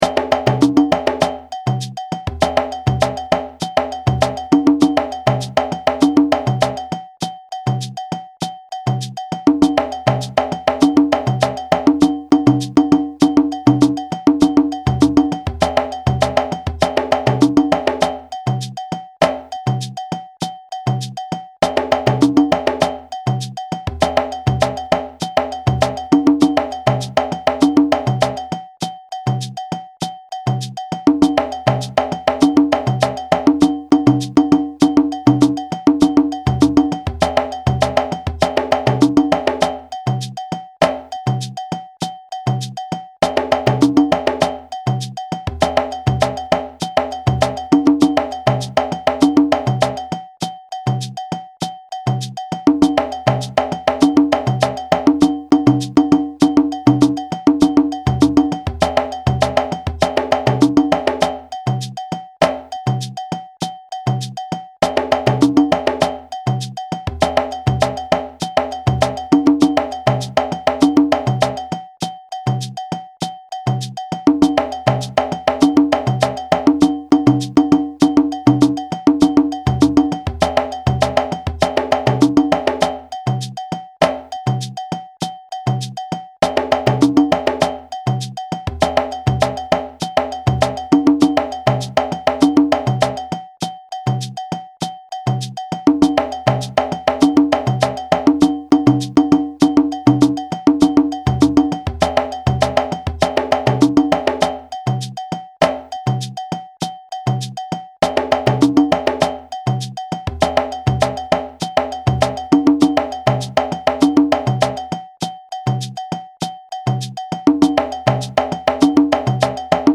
A Malinke djembe rhythm (Mali, Guinea) that is played while men harvest in the field to give them energy.
audio (with shekeré, bass & bell)
Kassa-1-fast-hh.mp3